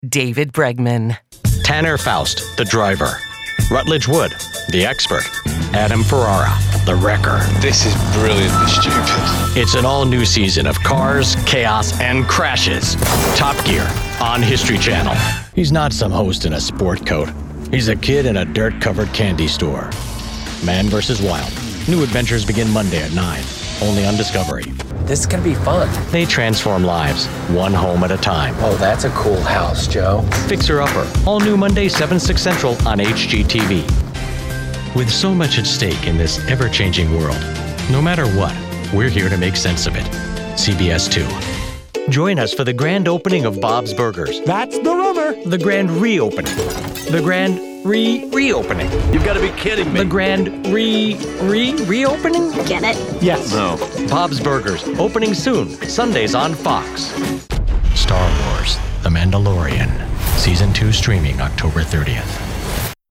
Guy next door, father, powerful, calming, general american Speech with quick turnaround
Promo Demo Reel